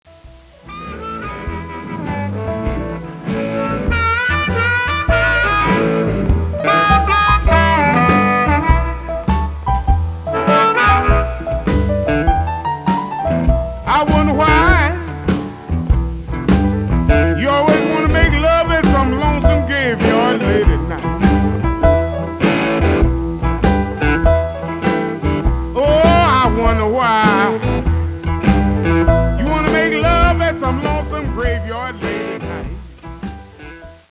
Electric guitar on all tracks
Electric and acoustic bass
Piano
Drums on tracks 1